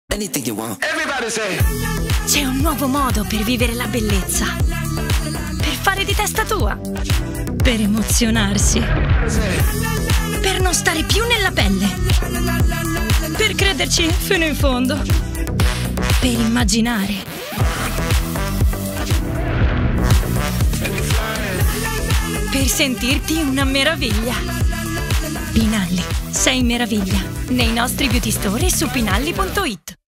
spot tv
caratterizzazioni varie da bambini, ragazzini a donne